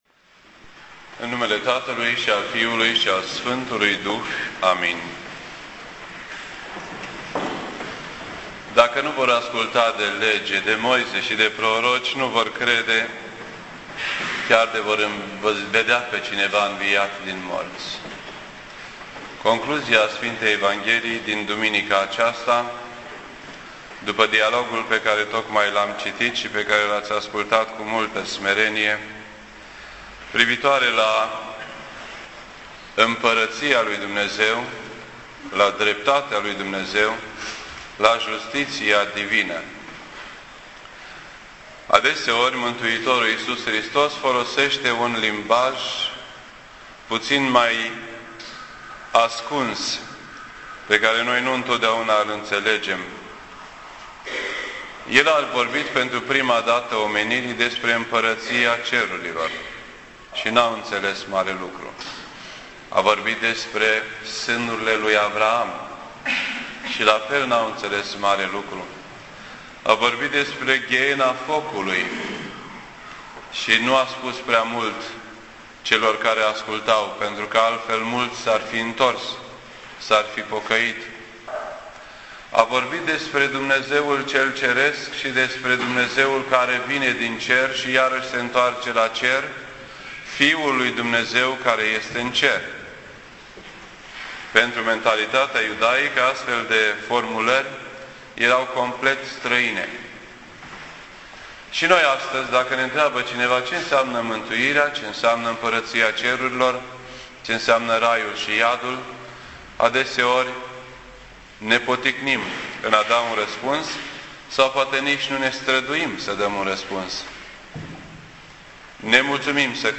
This entry was posted on Sunday, November 1st, 2009 at 6:28 PM and is filed under Predici ortodoxe in format audio.